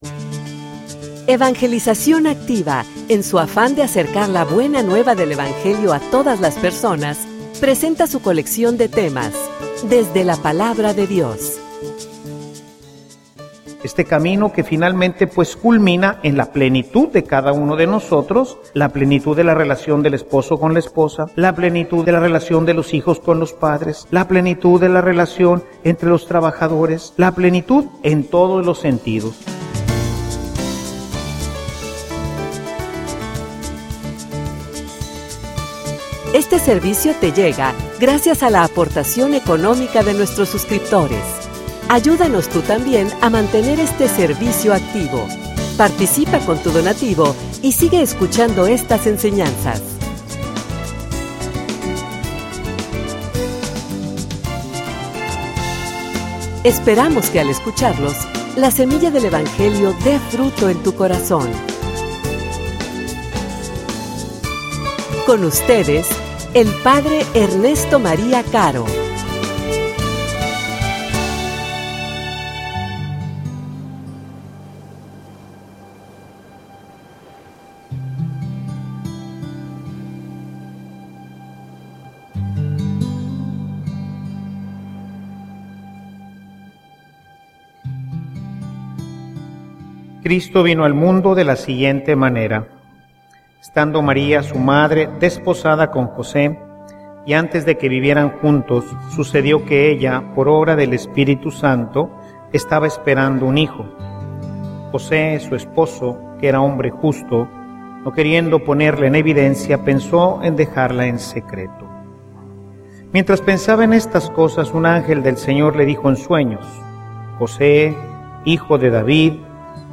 homilia_La_fe_de_san_Jose.mp3